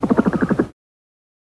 Dove Pigeon Coo, Low